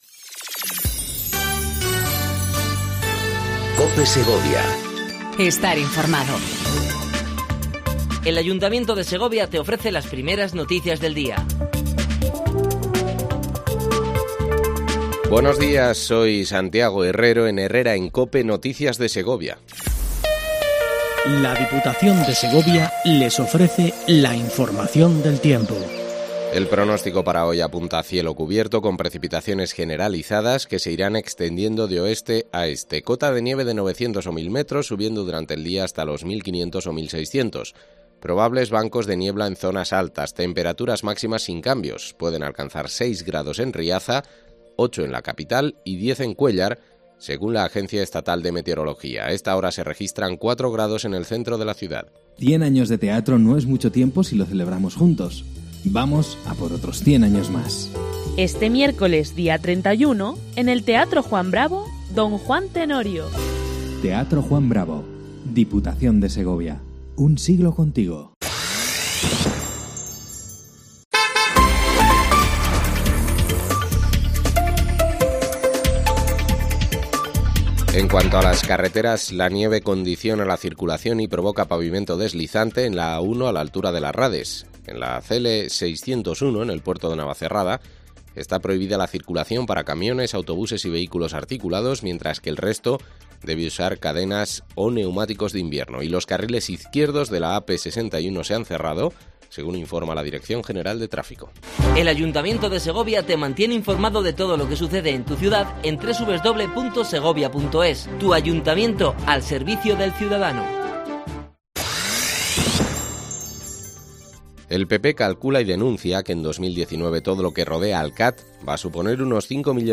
INFORMATIVO 07:55 COPE SEGOVIA 30/10/18
AUDIO: Primer informativo local en cope segovia